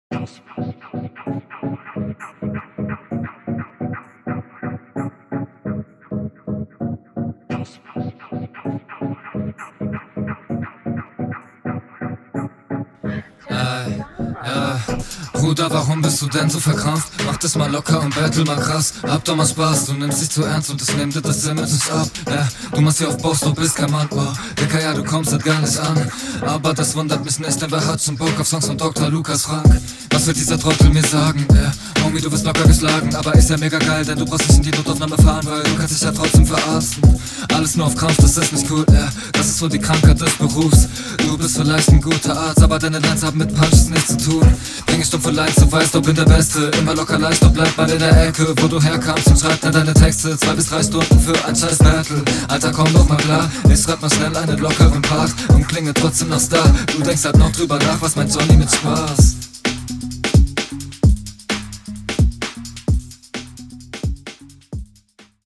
Flow: Stimme kommt locker.
sehr cooles Soundbild schonmal. bei den schnellen Passagen bist du nicht gaanz so sauber unterwegs …